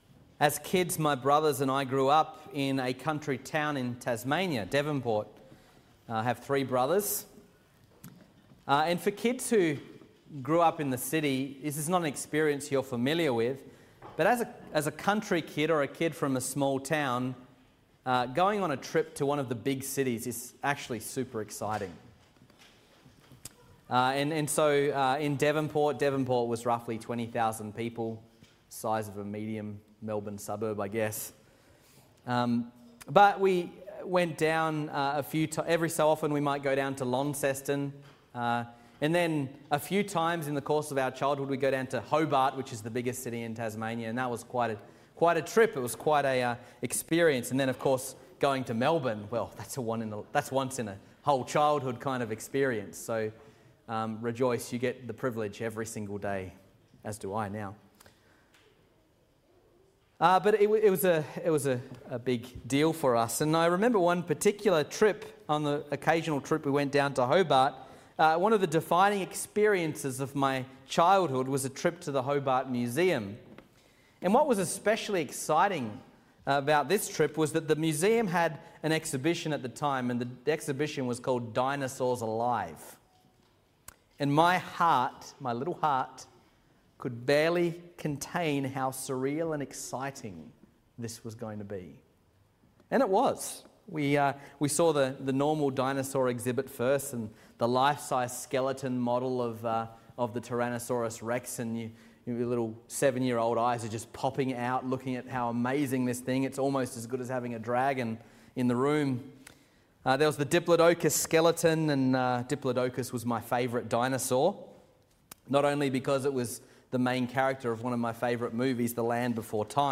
A Sermon About Dinosaurs